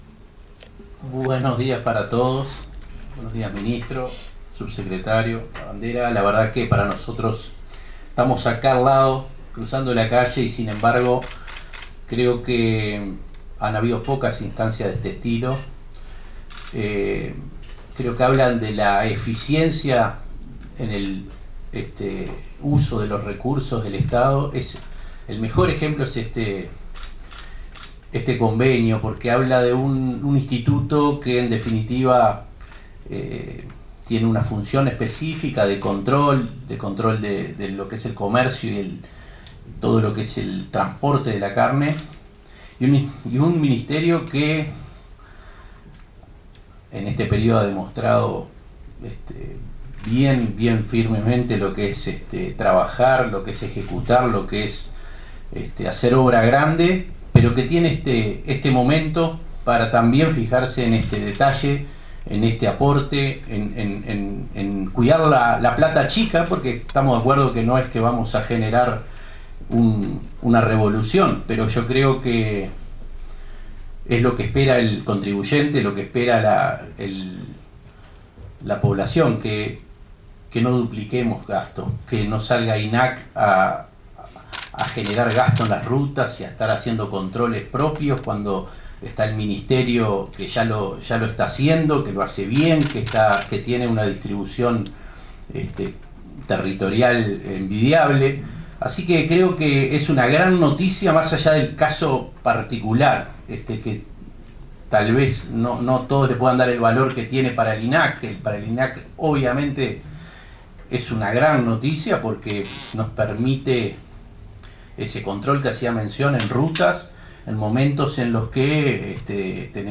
Acto de firma- Ministro Falero MTOP y Presidente Conrado Ferber INAC- mp3